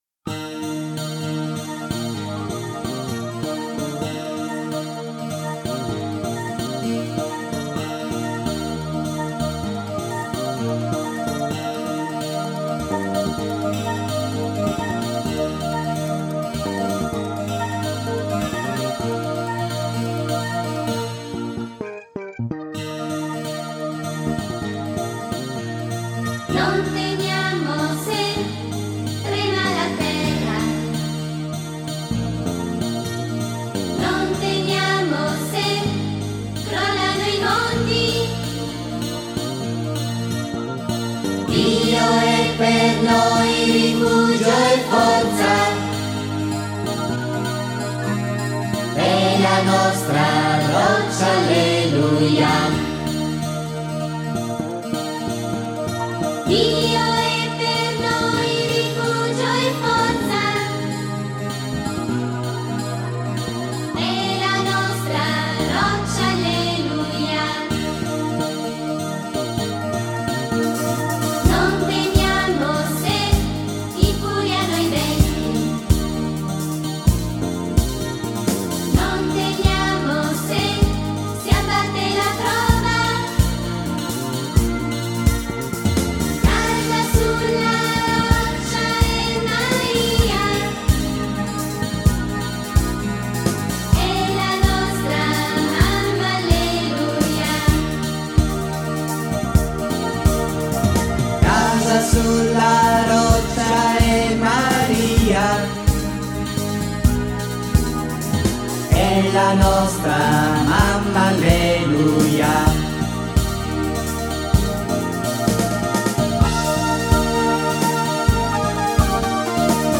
Canto per la Decina di Rosario e Parola di Dio: Dio è per noi rifugio e forza